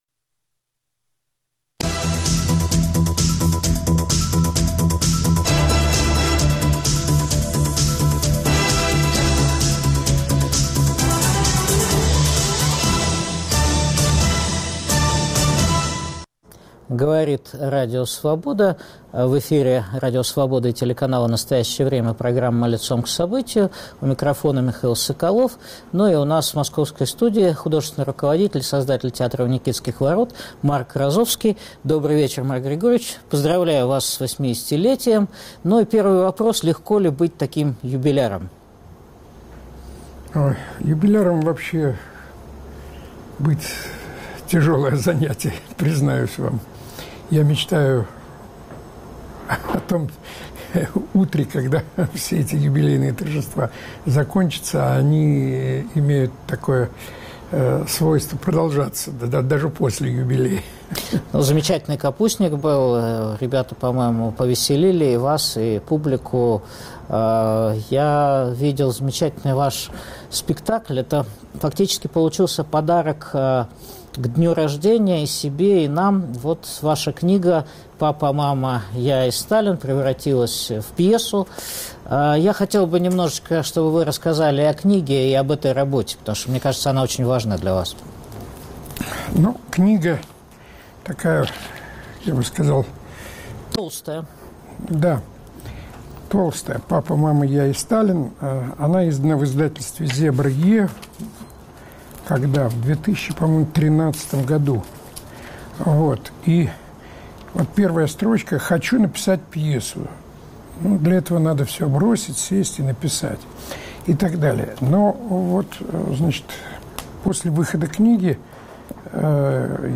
Говорим с художественным руководителем "Театра у Никитских ворот" Марком Розовским об искусстве и политике, о нацизме, сталинизме, терроризме, антисемитизме. Использованы отрывки из спектакля по пьесе Марка Розовского «Харбин-34» - о русском фашизме.